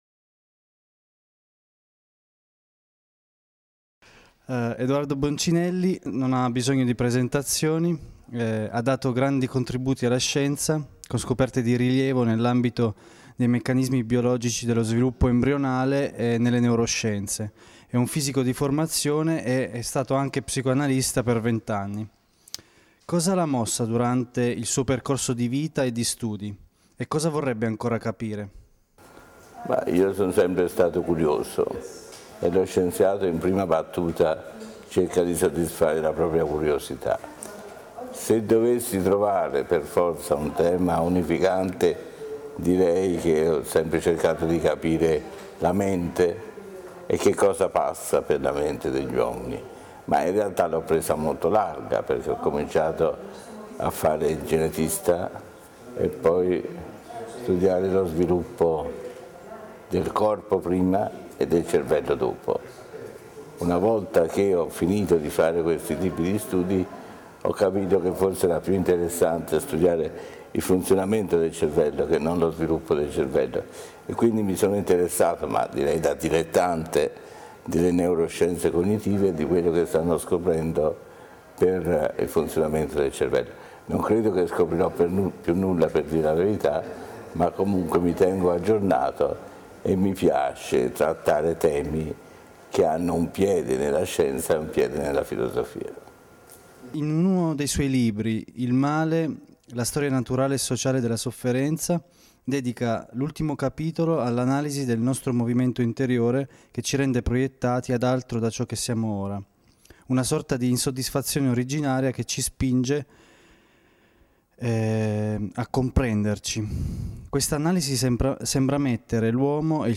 Intervista a Edoardo Boncinelli, famoso genetista italiano